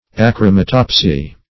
Search Result for " achromatopsy" : The Collaborative International Dictionary of English v.0.48: Achromatopsy \A*chro"ma*top"sy\, n. [Gr.